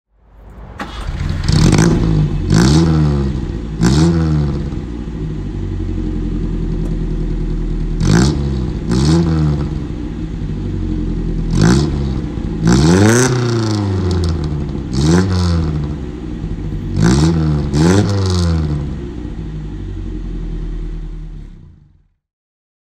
Lancia Fulvia 2C (1967) - Starten und Leerlauf
Lancia_Fulvia_2C_1967.mp3